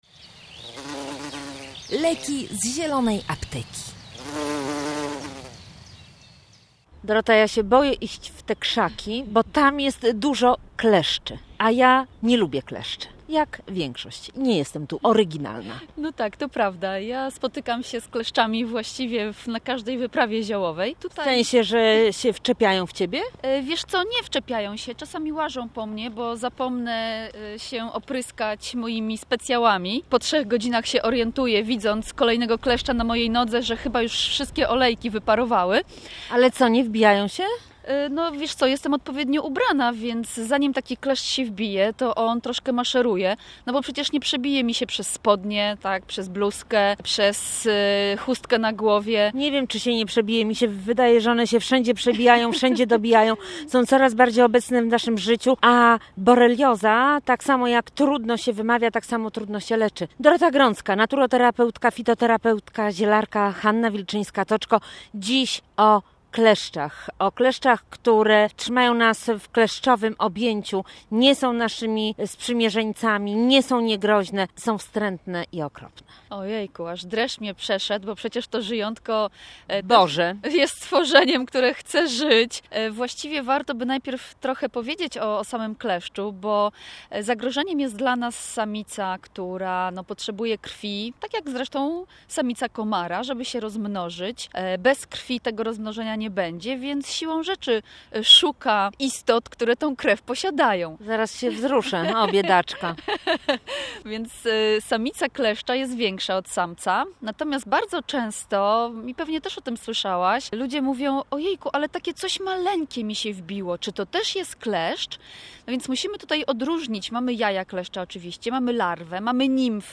Audycja była poświęcona kleszczom i metodom ochrony przed tymi nieprzyjemnymi, niebezpiecznymi pasożytami.